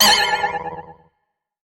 audio: Converted sound effects